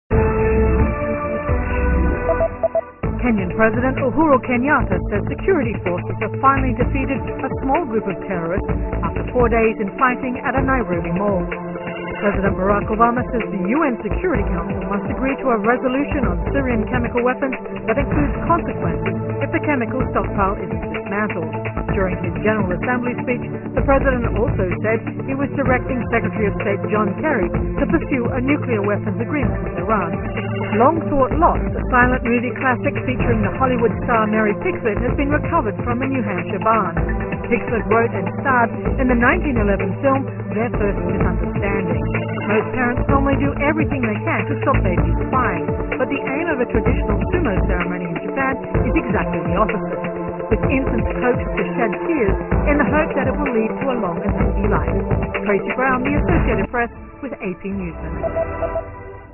在线英语听力室美联社新闻一分钟 AP 2013-10-02的听力文件下载,美联社新闻一分钟2013,英语听力,英语新闻,英语MP3 由美联社编辑的一分钟国际电视新闻，报道每天发生的重大国际事件。电视新闻片长一分钟，一般包括五个小段，简明扼要，语言规范，便于大家快速了解世界大事。